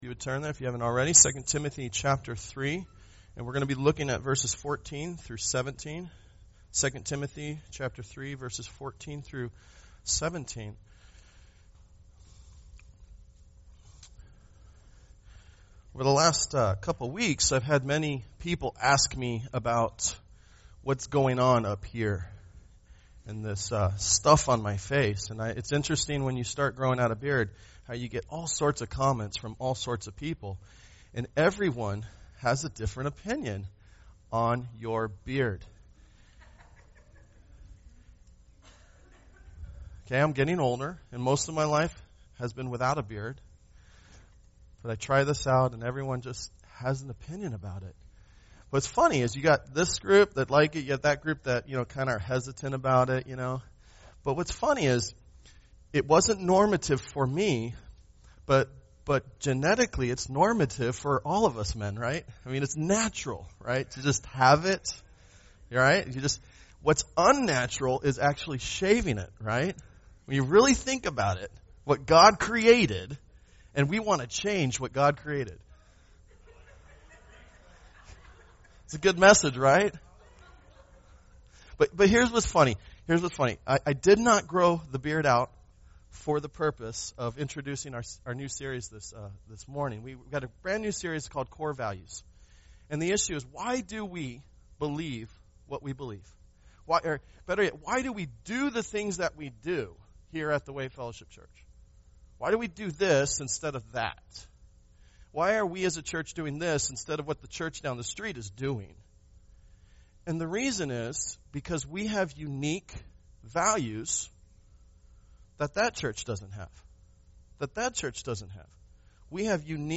Sunday Worship
Tagged with Core Values , Sunday Sermons